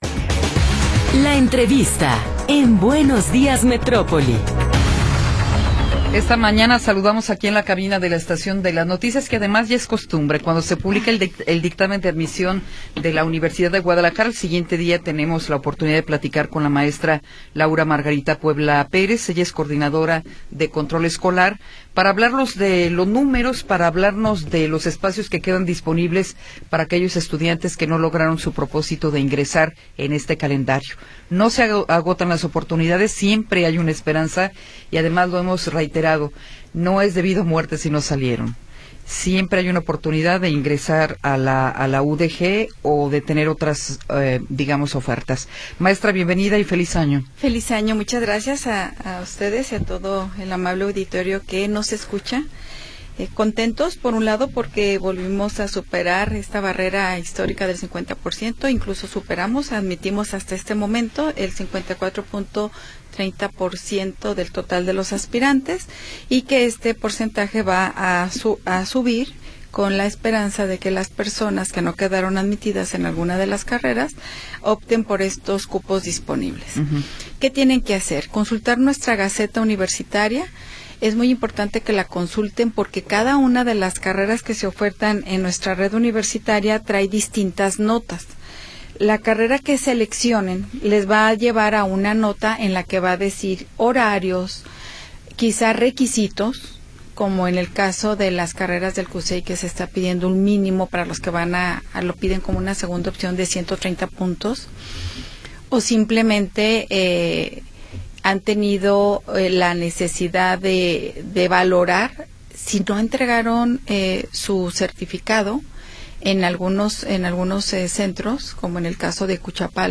entrevista-2.m4a